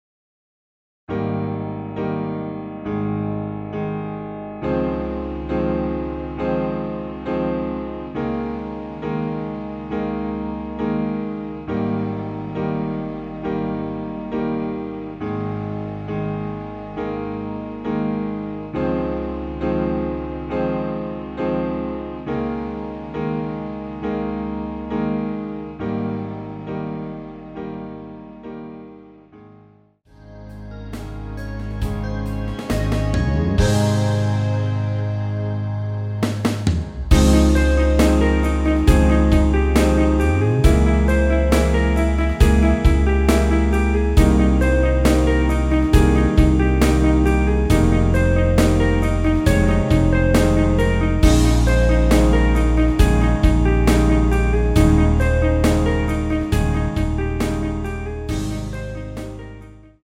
전주 없이 시작하는곡이라 전주 1마디 만들어놓았습니다.(미리듣기 참조)
원키에서(-4)내린 MR입니다.
Ab
앞부분30초, 뒷부분30초씩 편집해서 올려 드리고 있습니다.
중간에 음이 끈어지고 다시 나오는 이유는